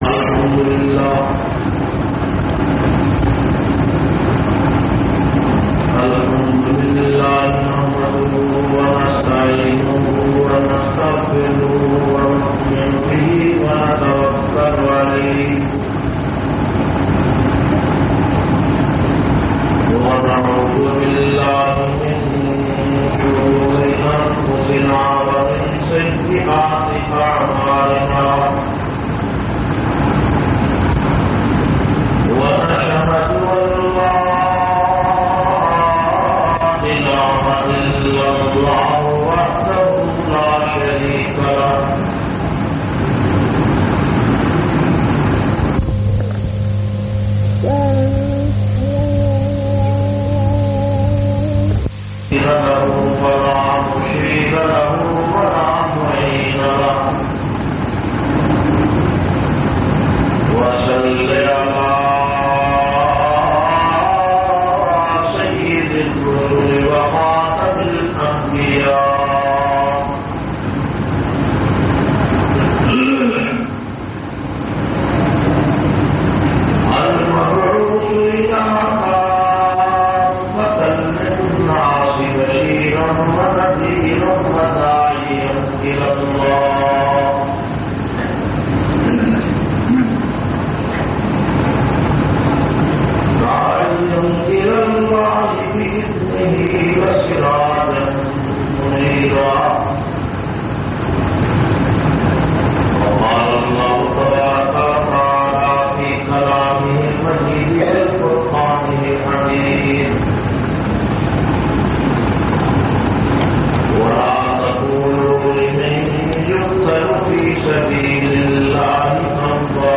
498- Hazrat Hussain Jumma khutba Jamia Masjid Muhammadia Samandri Faisalabad.mp3